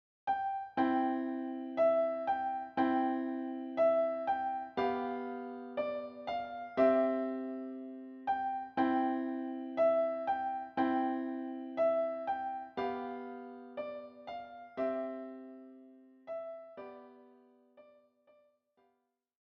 für Klavier vierhändig